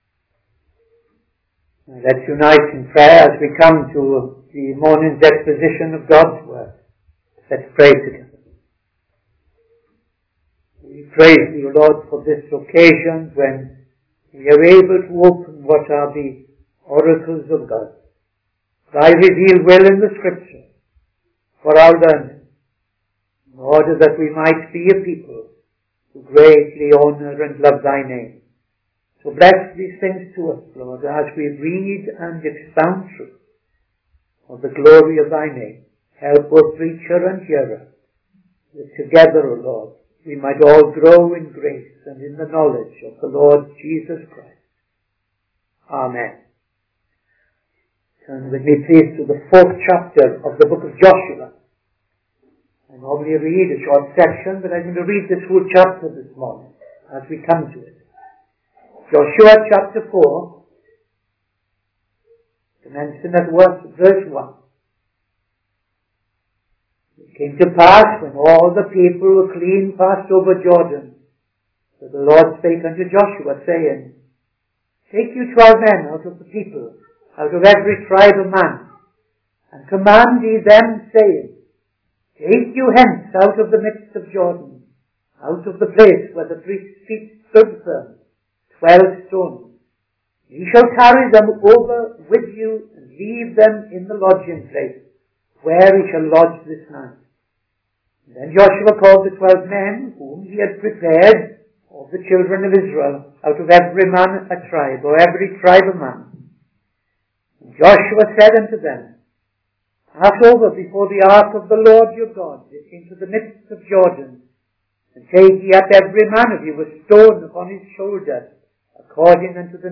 Midday Sermon - TFCChurch
Midday Sermon 14th December 2025